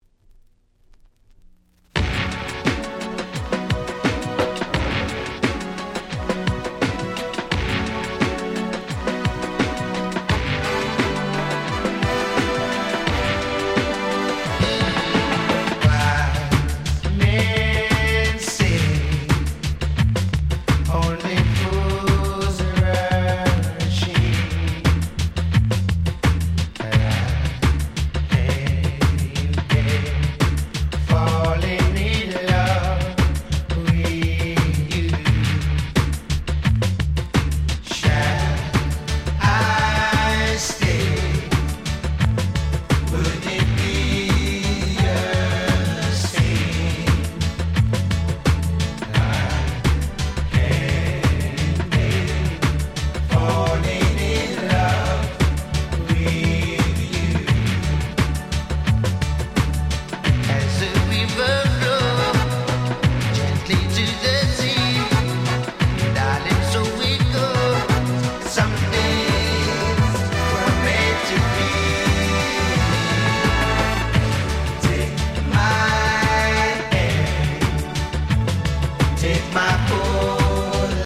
テーマは「グラウンドビート風味のPopsヒット」と言った所でしょうか。